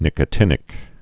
(nĭkə-tĭnĭk, -tēnĭk)